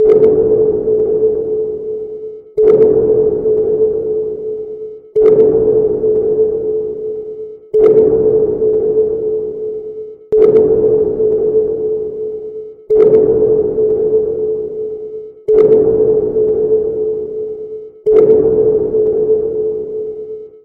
Подводная лодка проходит на близком расстоянии
podvodnaya_lodka_prohodit_na_blizkom_rasstoyanii_r40.mp3